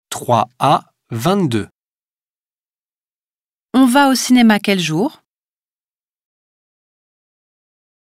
Folgende Hördialoge stehen zur Verfügung: